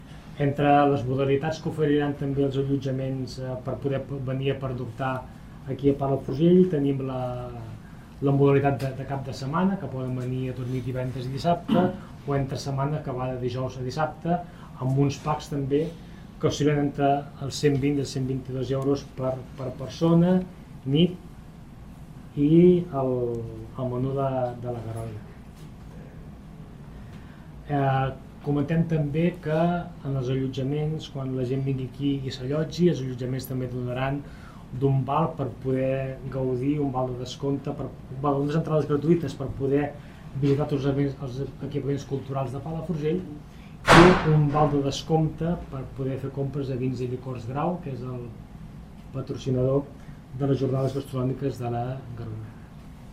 Ho explica el regidor de pomoció econòmica, Marc Piferrer.